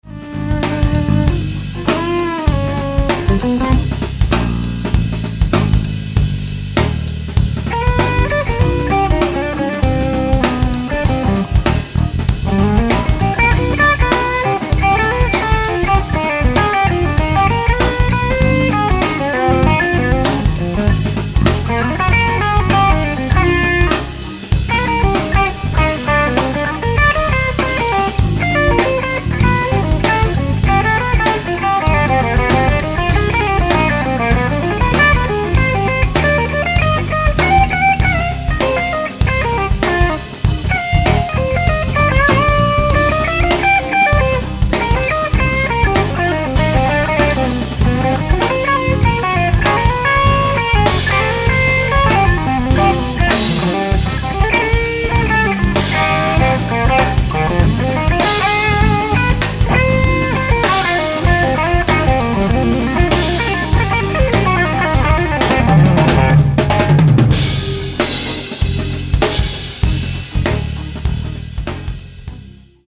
Here is a Zendrive test take. A bit dark but the solo was good.
Liked the playing, the tone sounded smaller somehow.